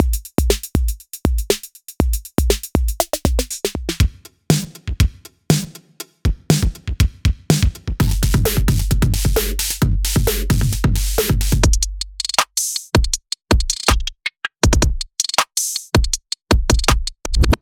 色々な電子ドラムの3点セット演奏